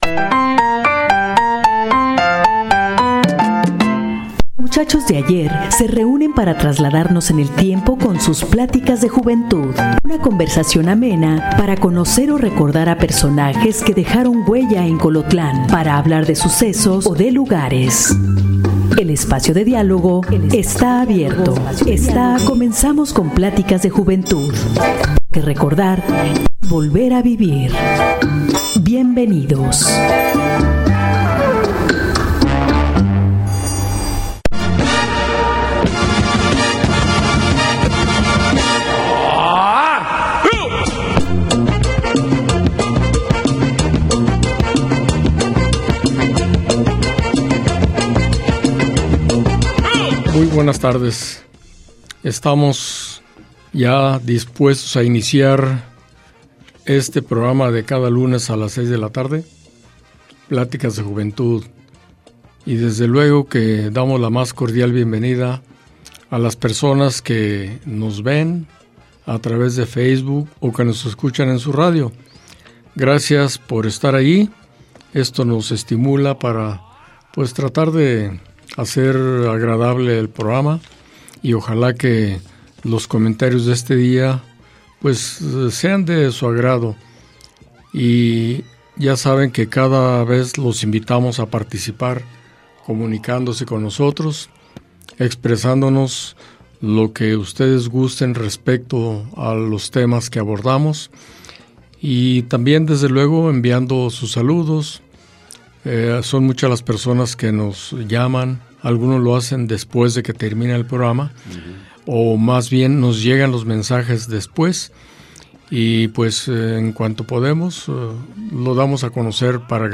Rescatar la riqueza oral de los pueblos para trasmitirla a nuevas generaciones a través de una plática amena e informal, es cometido principal del programa Pláticas de juventud, donde se escucha la voz de la experiencia y se reviven recuerdos de diferentes generaciones de hombres colotlenses. La vida del pueblo narrada por voces masculinas.